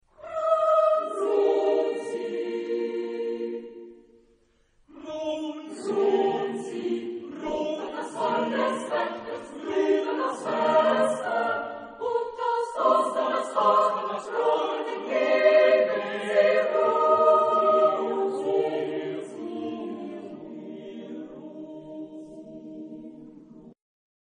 SATBB (5 voix mixtes) ; Partition avec réduction clavier pour répétition.
Pièce chorale. Romantique.